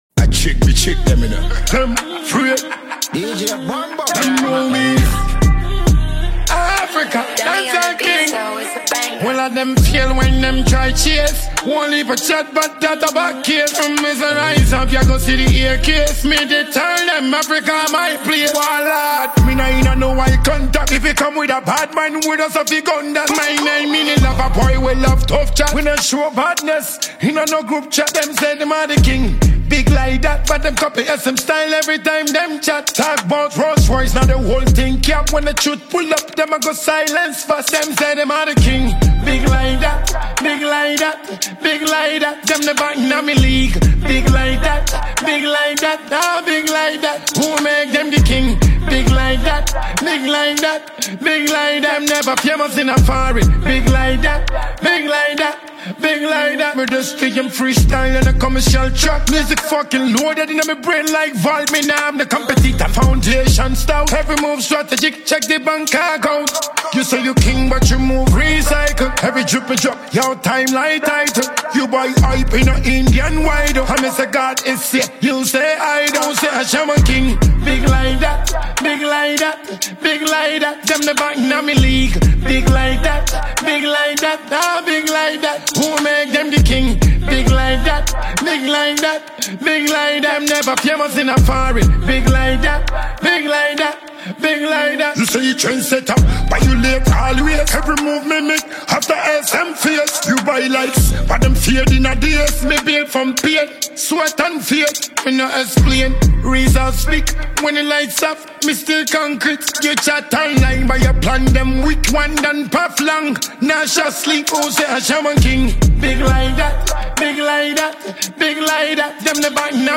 Highly rated Ghanaian dancehall musician